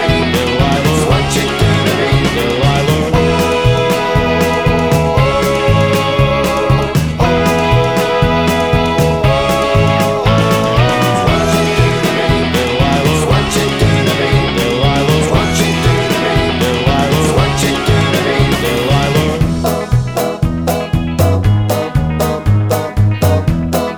no Backing Vocals Rock 'n' Roll 3:46 Buy £1.50